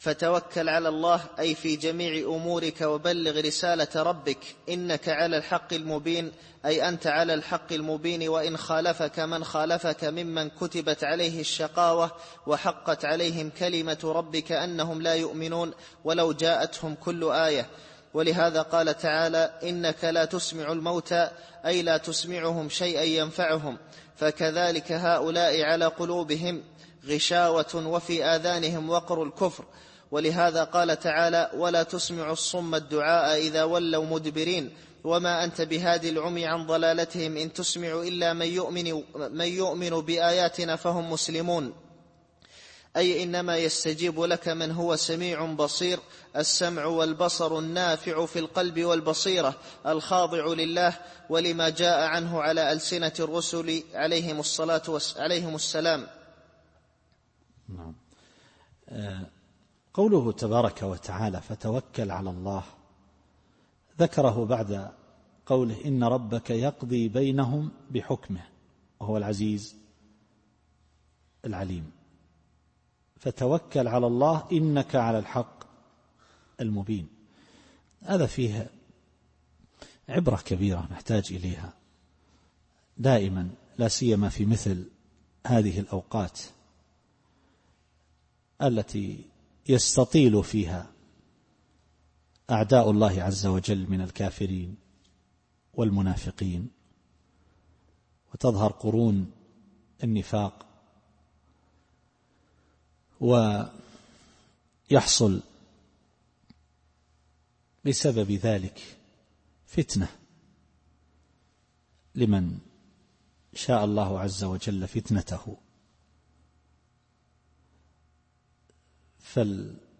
التفسير الصوتي [النمل / 79]